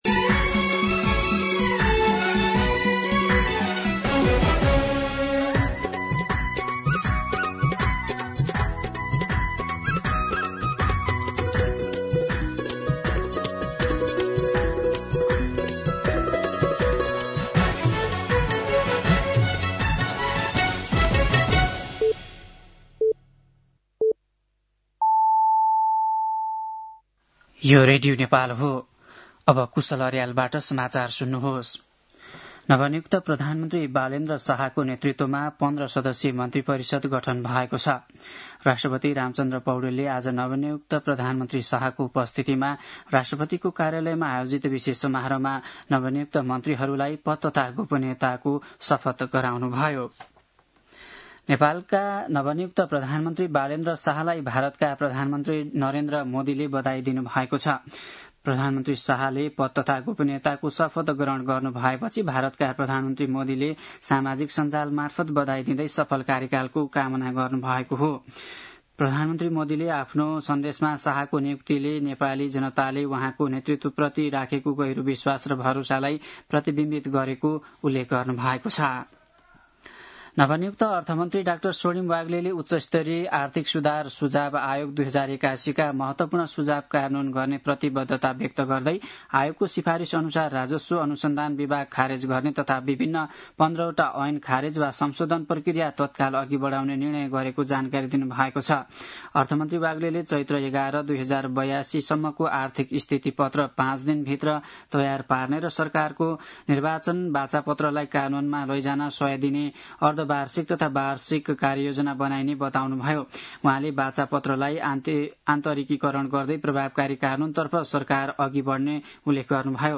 दिउँसो ४ बजेको नेपाली समाचार : १३ चैत , २०८२
4-pm-Nepali-News-6.mp3